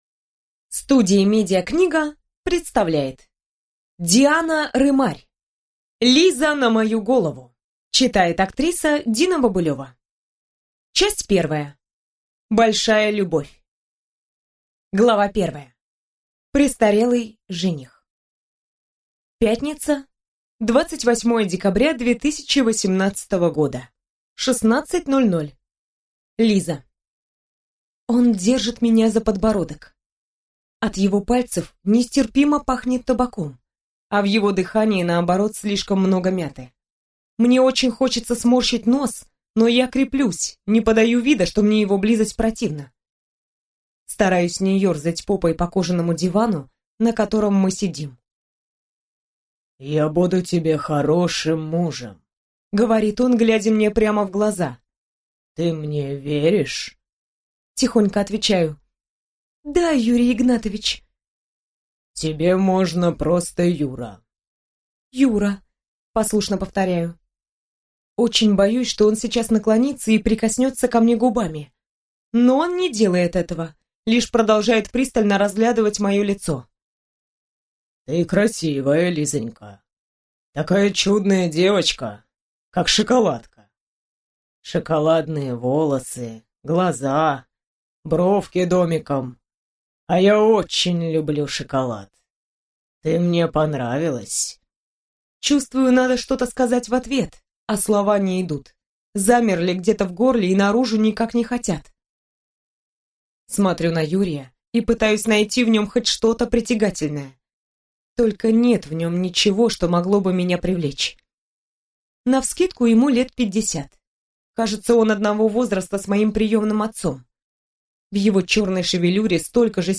Аудиокнига Лиза на мою голову | Библиотека аудиокниг